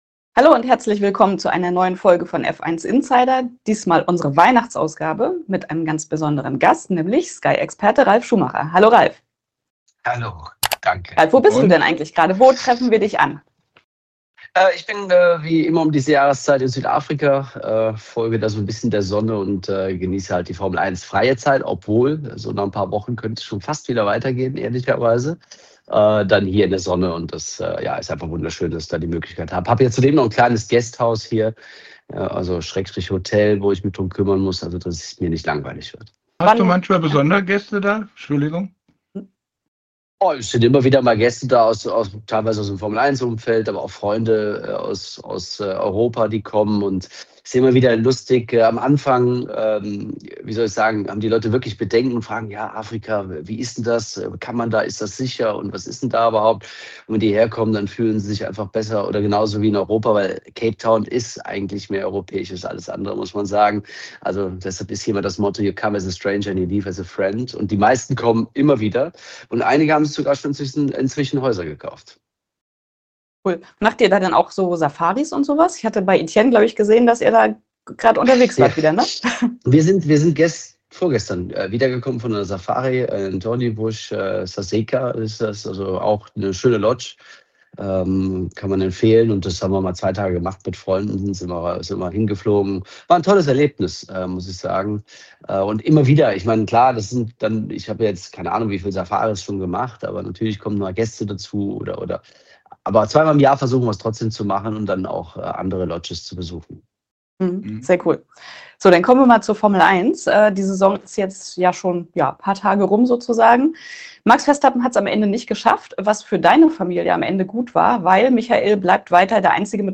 Interview mit Ralf Schumacher | Formel 1 ~ Formel 1 Podcast
interview-mit-ralf-schumacher-formel-1.mp3